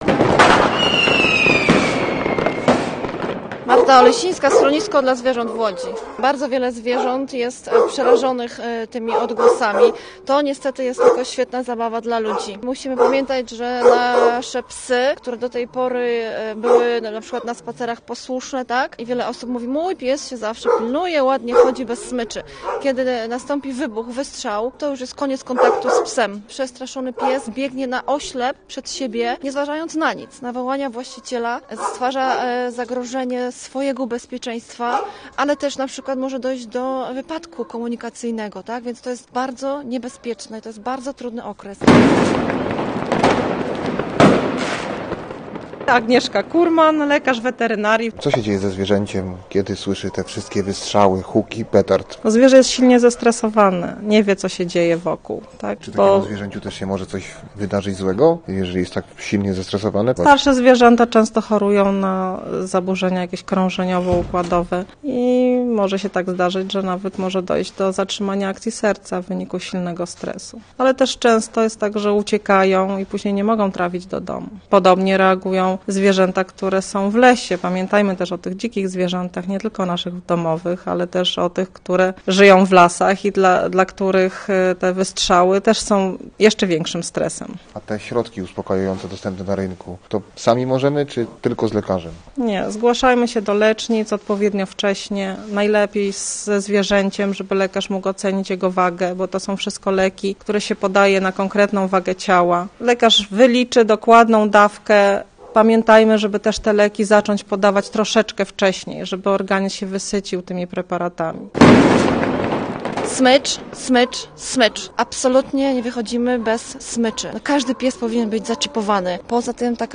Posłuchaj relacji: Nazwa Plik Autor Zwierzęta kontra fajerwerki audio (m4a) audio (oga) Warto przeczytać Daniel Spaleniak zagrał koncert w Radiu Łódź [ZDJĘCIA] 18 maja 2024 Noc Muzeów 2024.